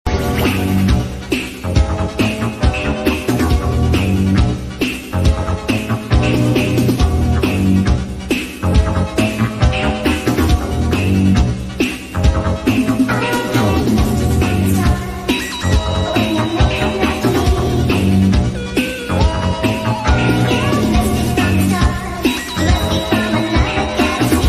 A Train Sound Effect Free Download
A Train